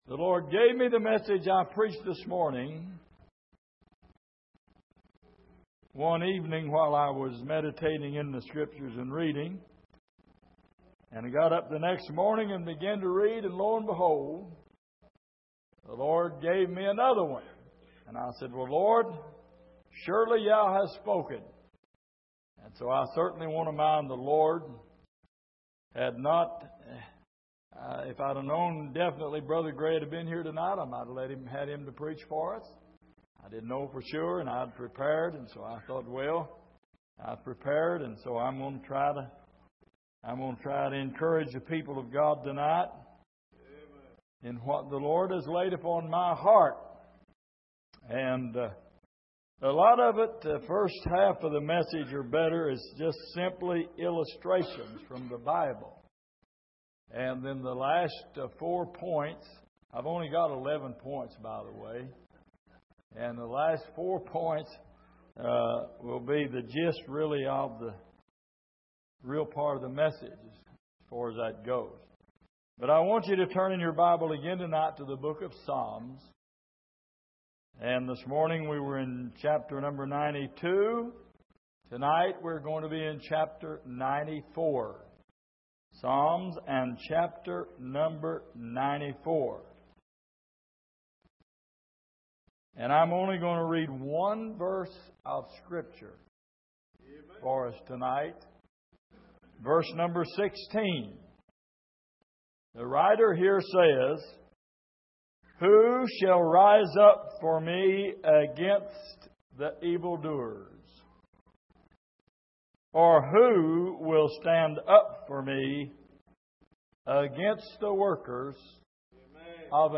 Passage: Psalm 94:16 Service: Sunday Evening